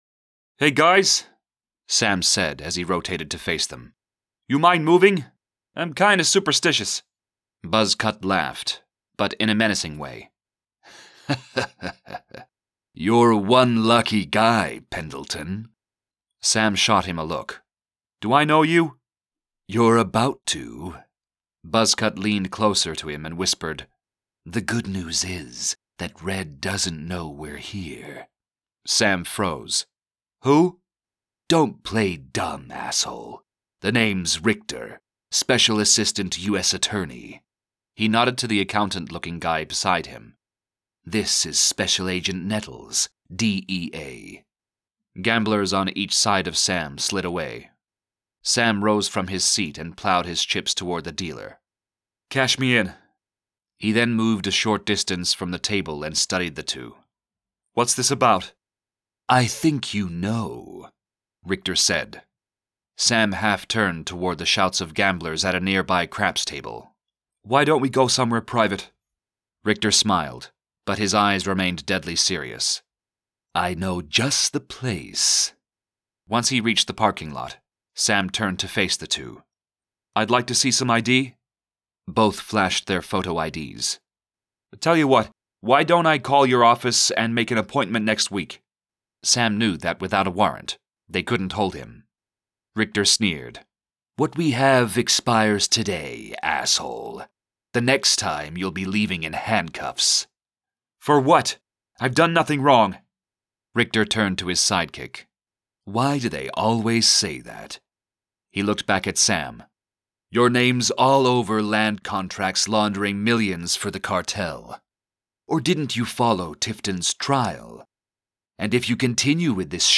Narrator
Excerpt from Conspiracy of Lies by Richard S. Rachlin -